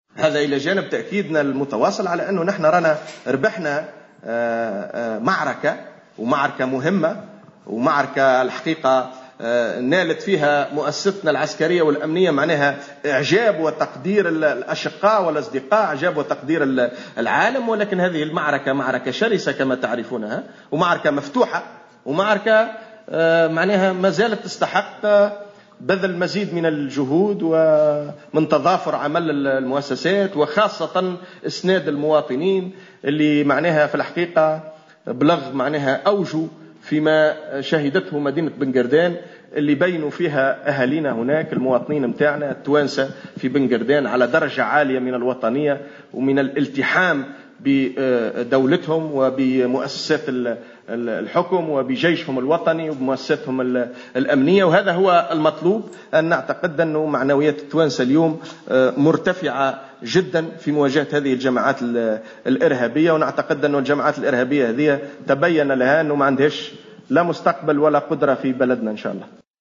قال خالد شوكات الناطق الرسمي باسم رئاسة الحكومة في تصريح للجوهرة أف أم على هامش مجلس وزاري عقد اليوم الأربعاء 09 مارس 2016 بقصر الحكومة بالقصبة إن أهالي بن قردان بينوا أنهم على درجة عالية من الوطنية في التحامهم بالمؤسسة الأمنية والعسكرية.